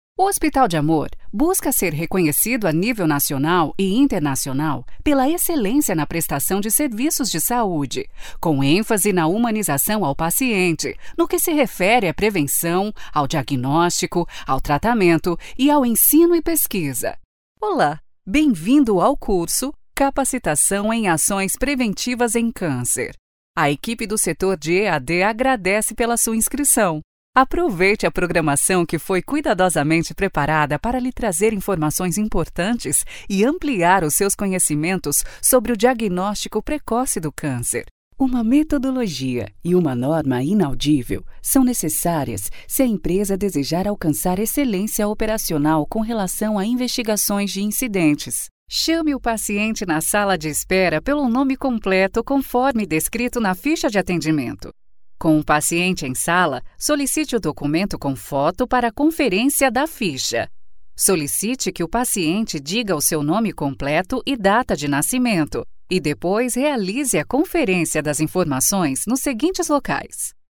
16 years of experience, my interpretation is smooth and pleasant, with a captivating melodic style, bringing credibility and adding value to your brand.
brasilianisch
Sprechprobe: eLearning (Muttersprache):